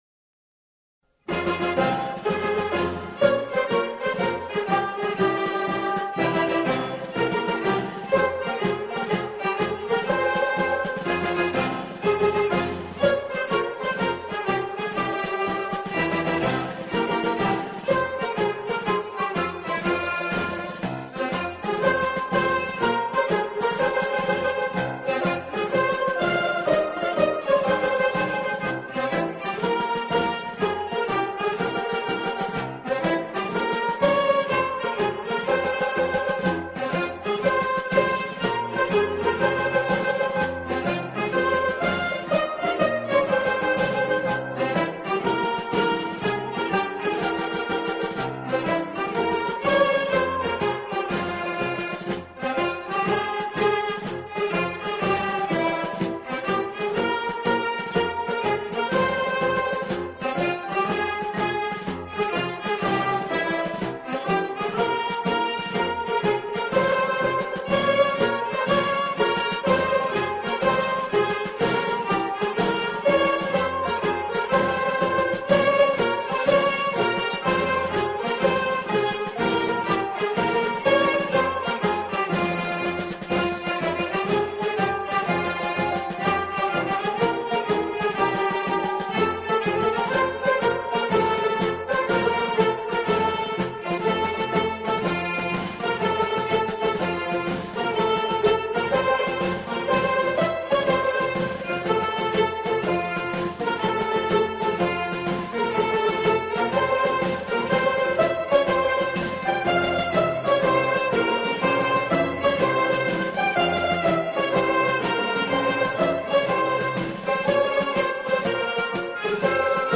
سرودهای دهه فجر
بی‌کلام